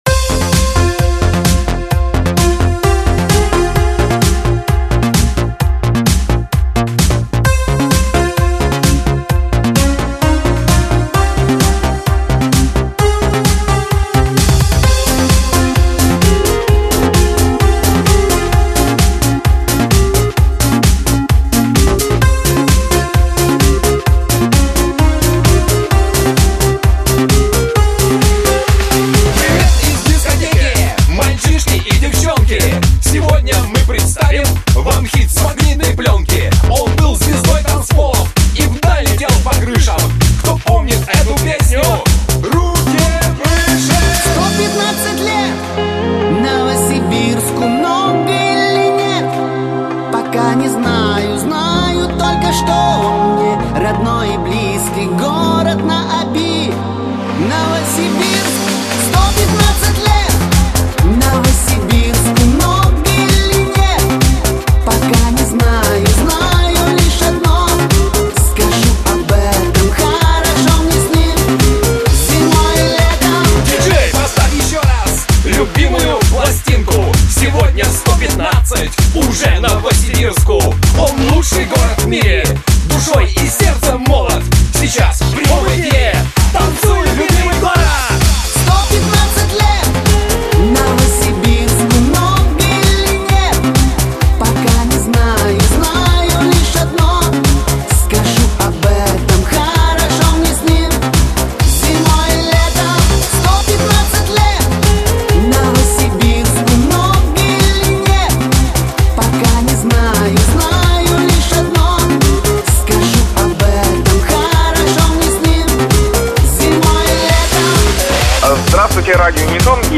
Категория: Шансон